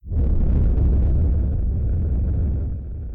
Machine ambient sounds
fire.ogg